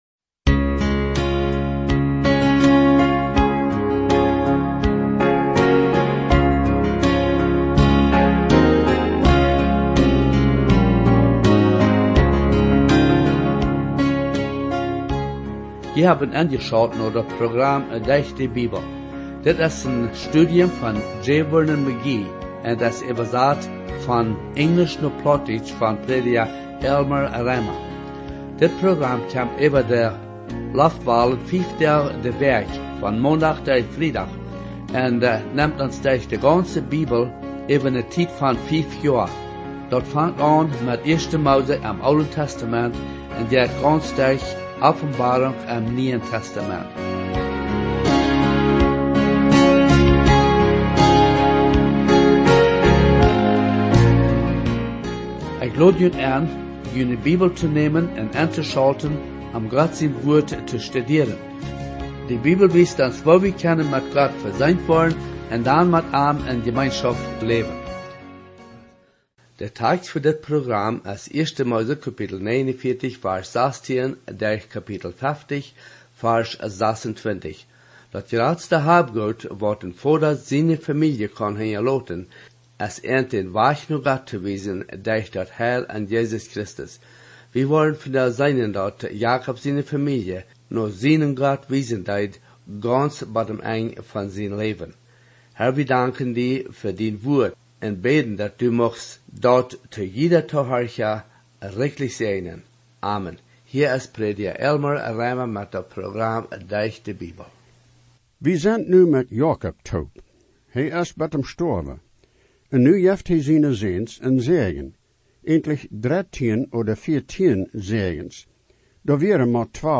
Dee sent jeplont fa een däachlichet 30 Minuten Radio program to haben, daut de horcha sistematish derch de gaunze Bibel nemt en Fief Joa.